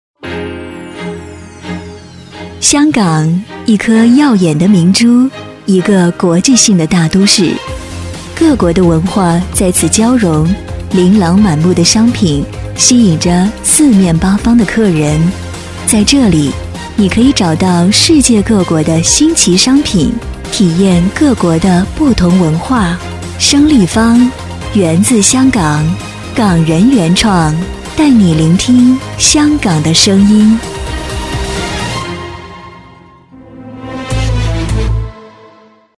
女158-品牌广告【声立方-高端大气知性】
女158-中英双语 质感磁性
女158-品牌广告【声立方-高端大气知性】.mp3